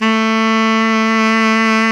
Index of /90_sSampleCDs/Roland L-CD702/VOL-2/SAX_Tenor mf&ff/SAX_Tenor mf
SAX TENORM09.wav